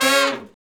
Index of /90_sSampleCDs/Roland LCDP06 Brass Sections/BRS_R&R Horns/BRS_R&R Falls